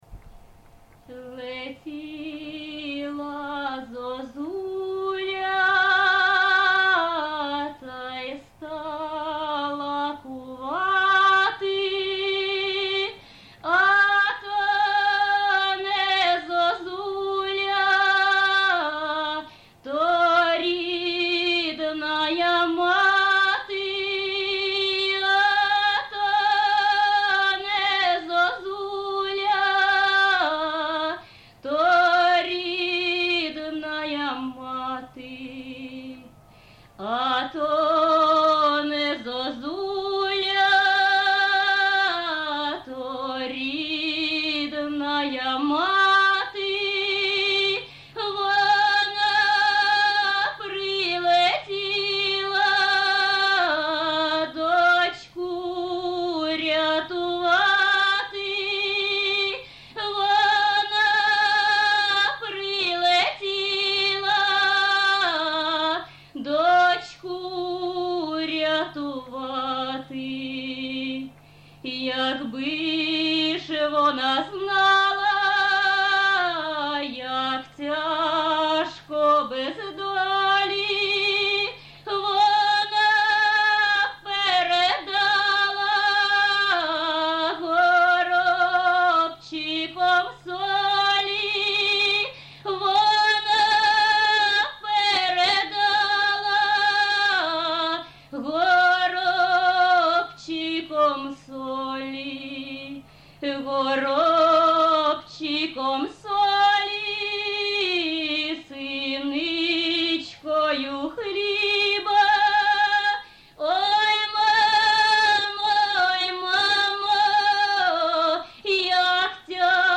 ЖанрПісні з особистого та родинного життя
Місце записус. Гусарівка, Барвінківський район, Харківська обл., Україна, Слобожанщина
(Виконавиця співає не в традиційній, а в сценічній манері)